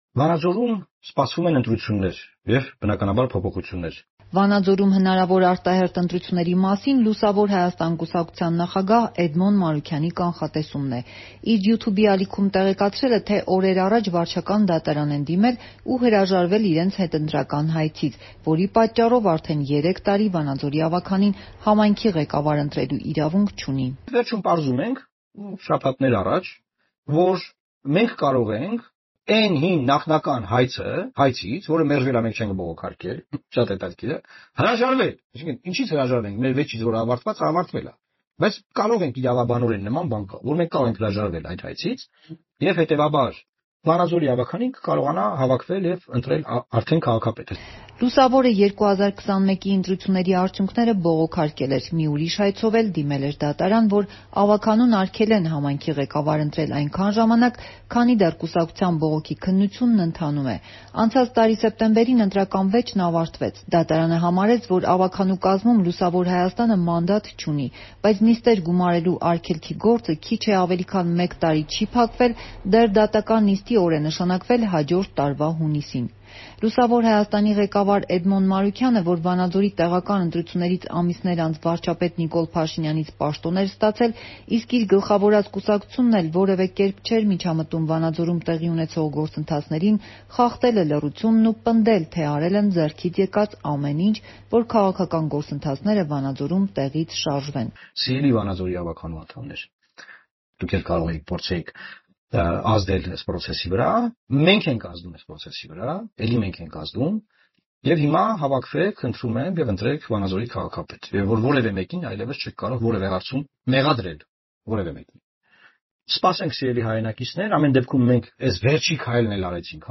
Ավելի մանրամասն՝ «Ազատության» ռեպորտաժում.